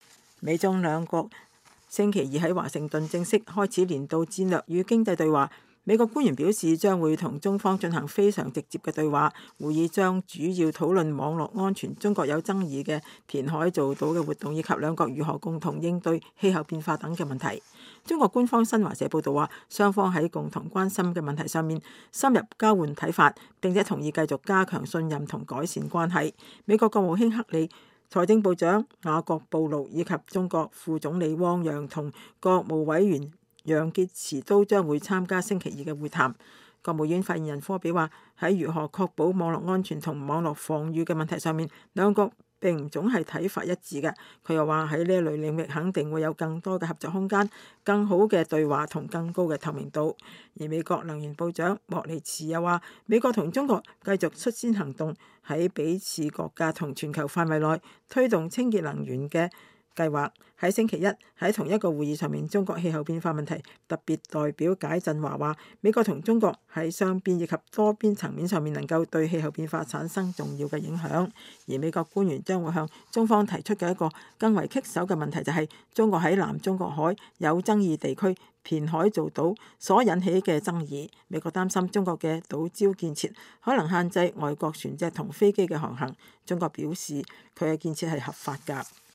美國與中國星期二(6月23日)在華盛頓舉行的年度戰略及經濟對話開幕時美國國務卿克里首先講話。